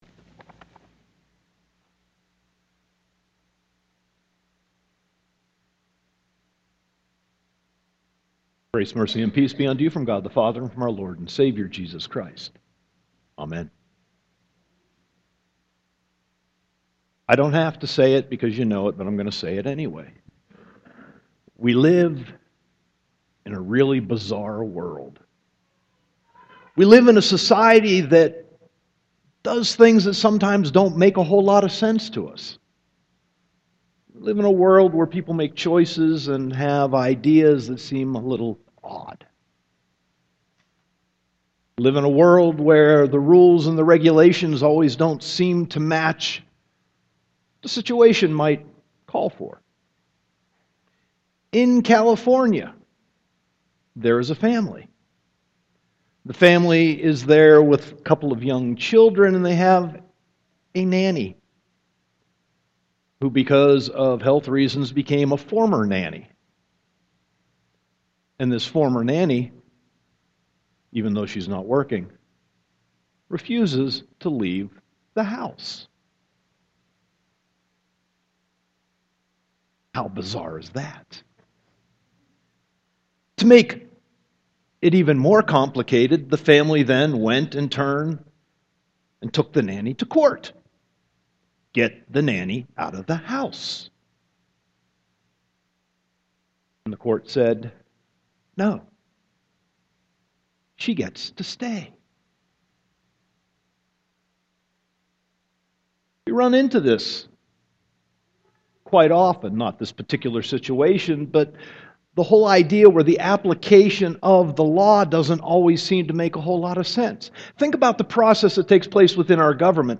Sermon 6.29.2014 -